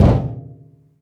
metal_drum_impact_thud_04.wav